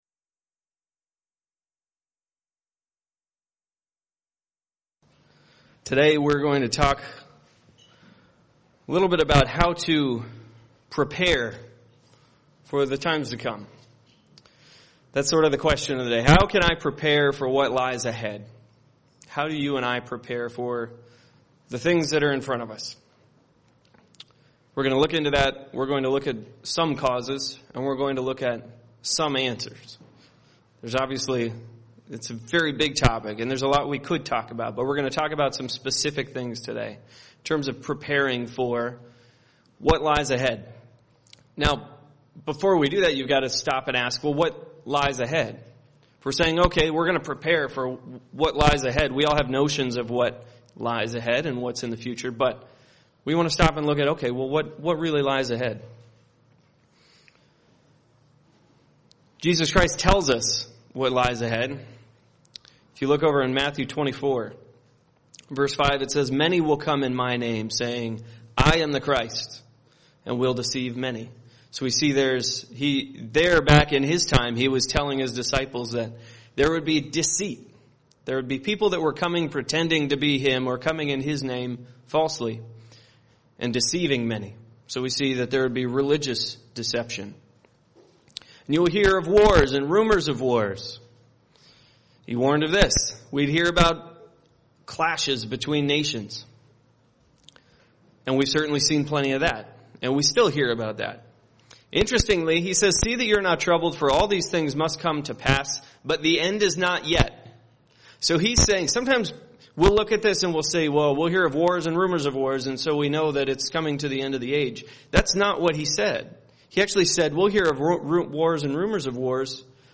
Learn more in this Kingdom of God seminar.
UCG Sermon Studying the bible?